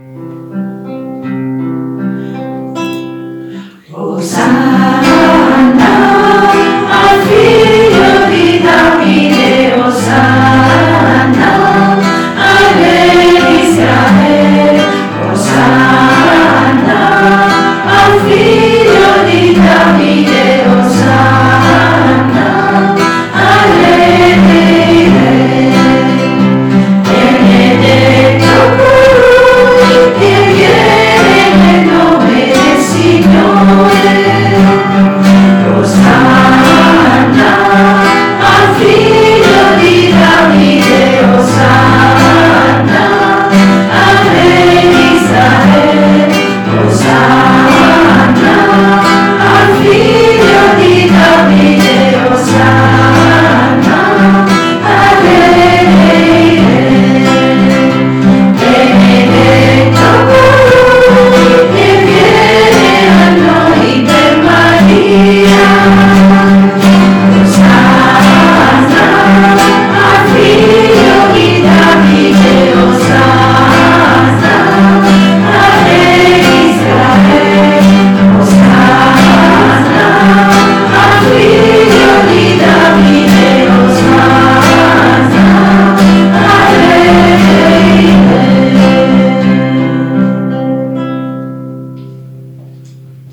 Canto per la Decina: Osanna al Figlio di Davide